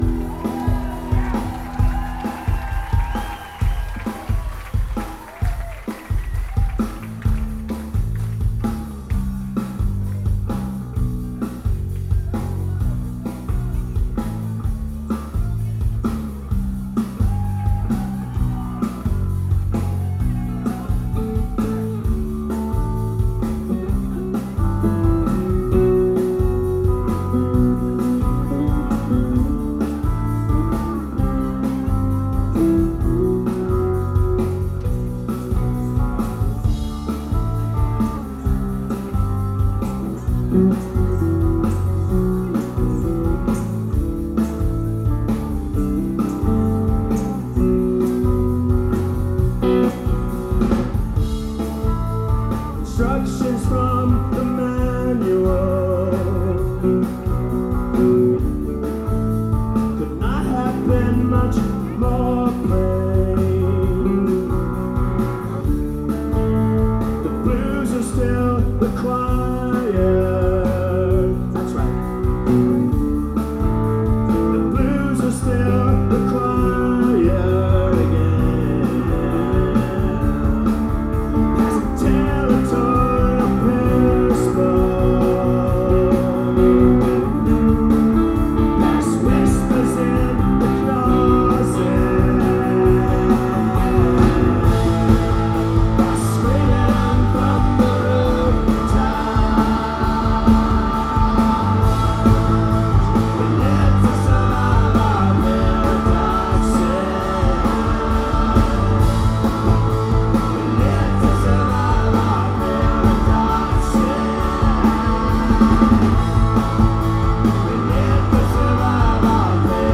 Live In 1997